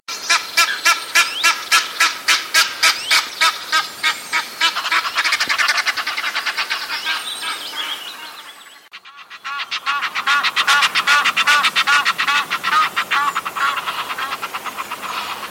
nijlgans
🇬🇧 English: Egyptian goose
♪ contactroep
nijlgans_roep.mp3